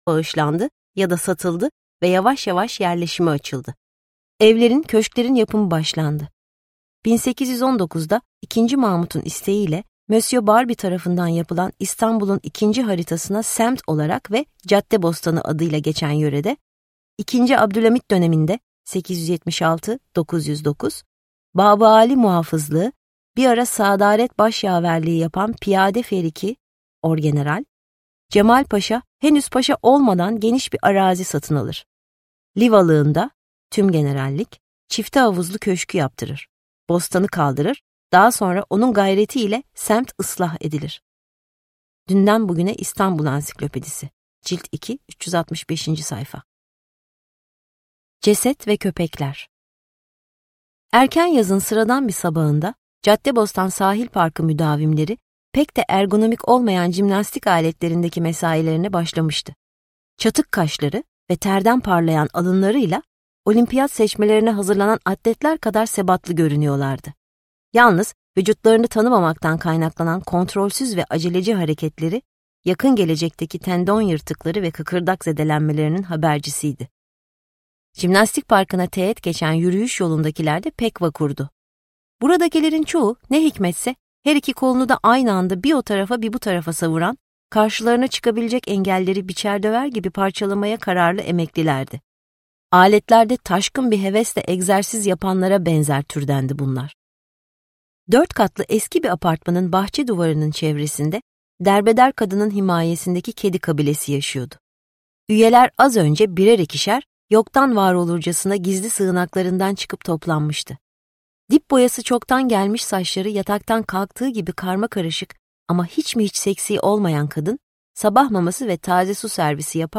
Cadıbostanı Cinayeti - Seslenen Kitap